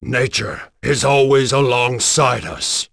Kaulah-Vox_Victory.wav